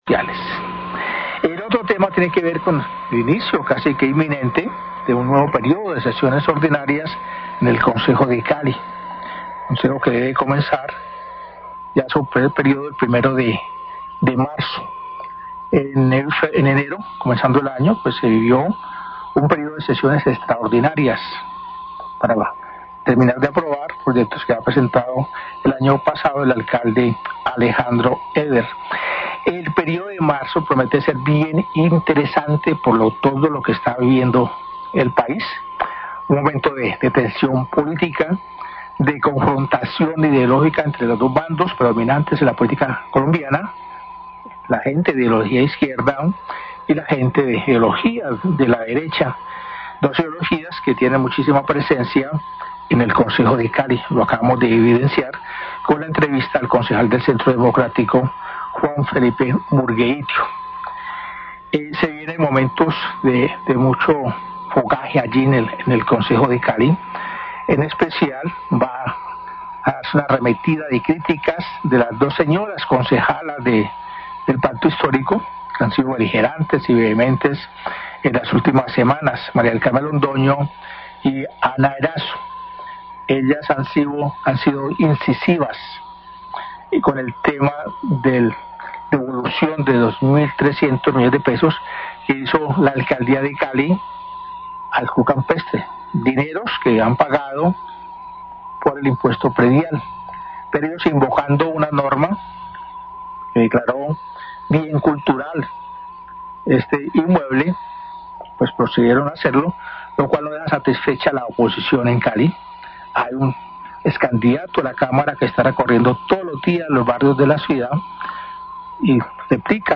Radio
análisis